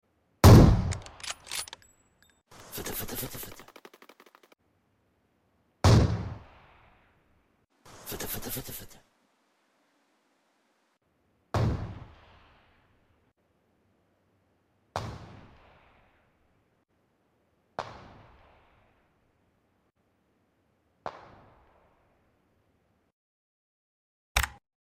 AWM Sound PUBG MOBILE 10m Sound Effects Free Download
AWM Sound PUBG MOBILE 10m to 300m